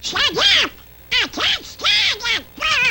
Download Free Donald Duck Sound Effects